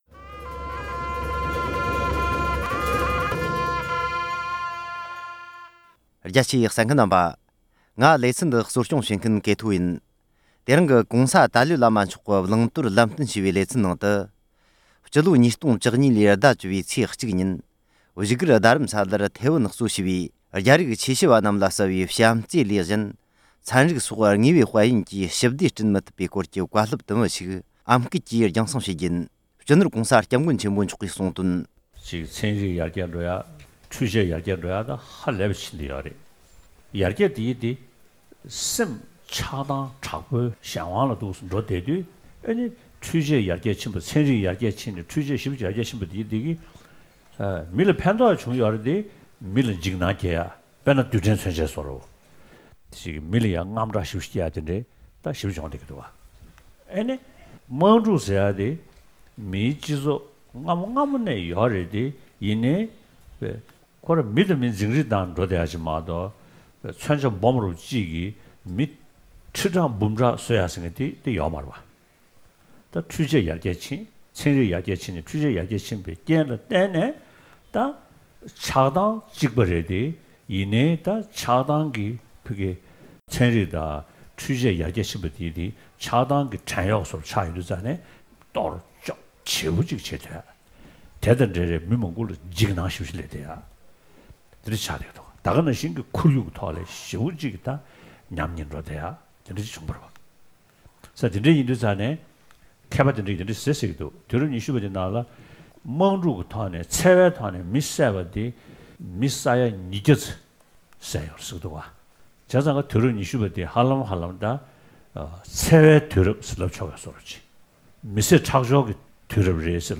ཨམ་སྐད་དུ་སྒྲིག་སྦྱོར་དང་།